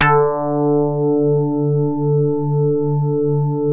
JAZZ HARD D2.wav